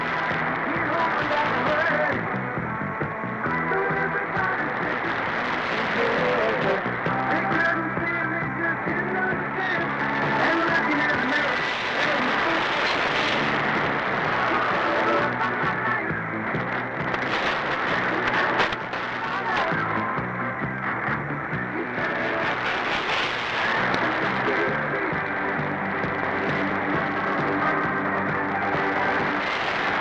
Here are the loggings from Georgia, USA